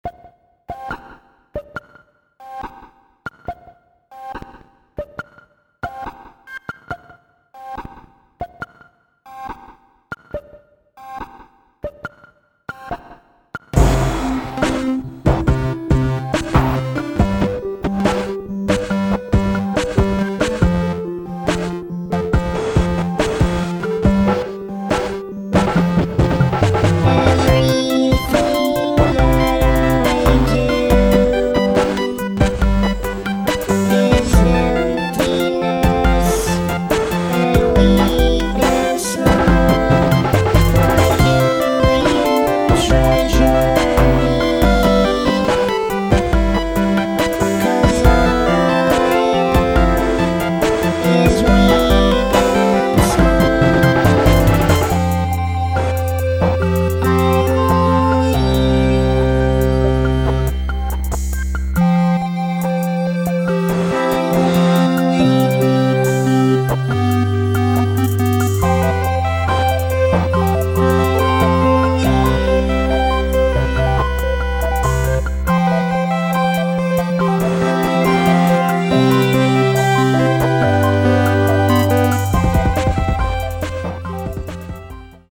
use Gameboys, cheap keyboards, electronic toys and vocoders